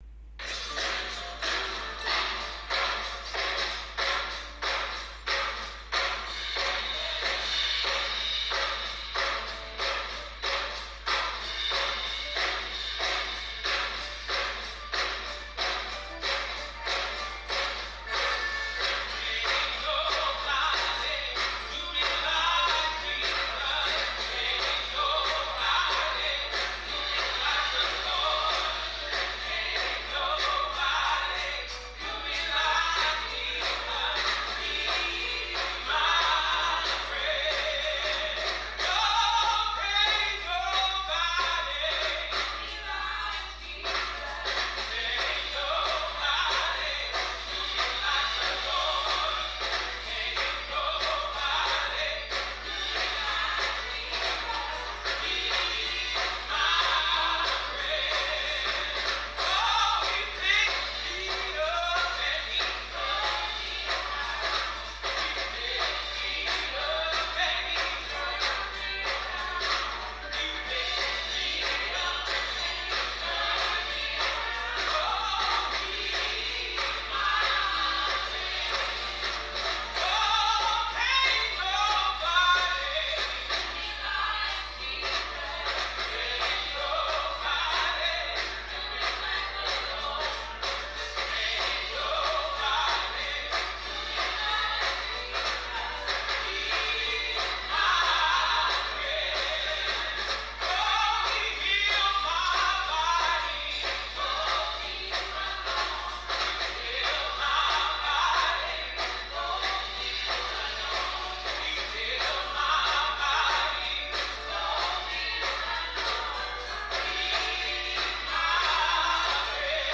Devotion Praise